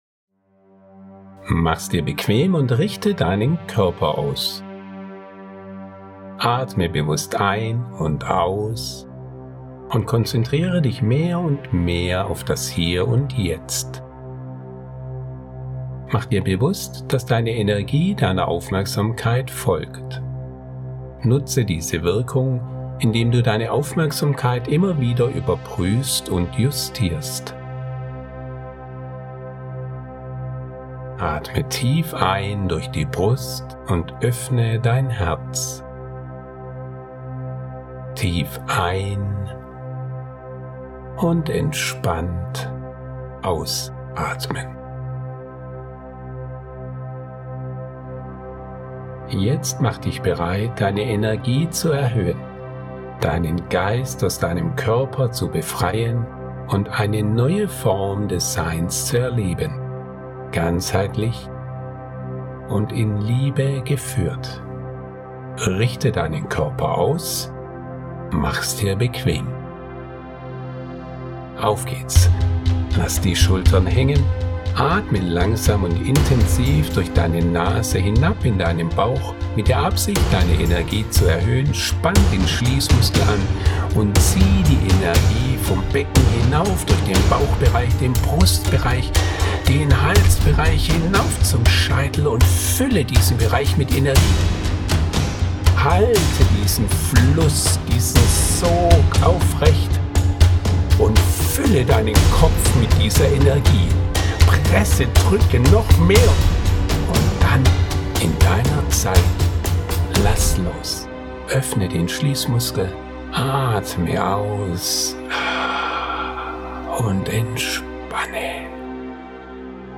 • 6,5 Minuten Meditation
• aufgenommen mit 432 Hz zur Steigerung der Wirkung